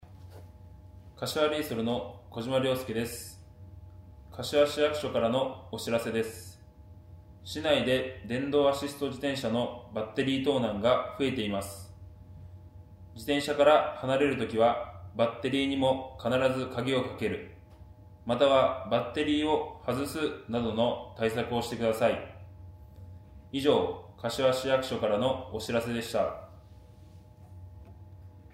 4月15日（水曜日）より、もっと身近に防犯を意識してもらえるような、思わず耳に残る、やさしくて分かりやすい音声を使用します。
小島 亨介（サッカーチーム 柏レイソル 所属選手）